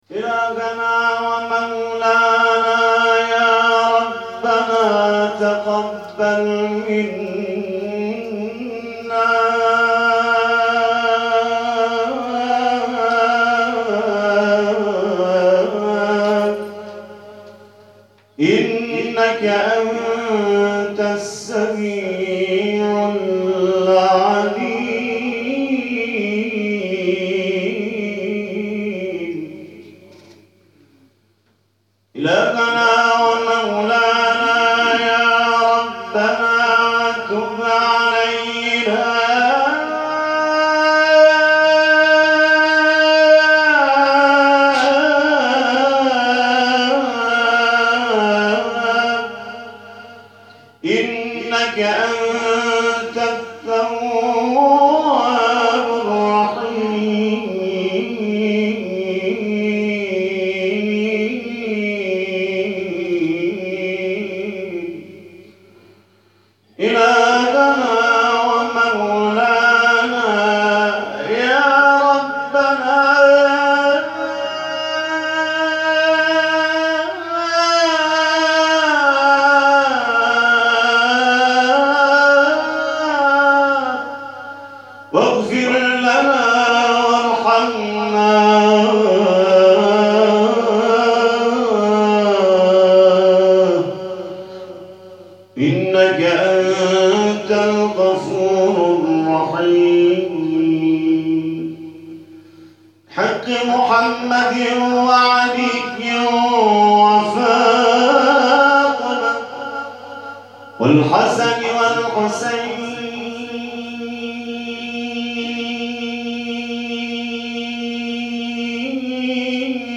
دعای ختم قرآن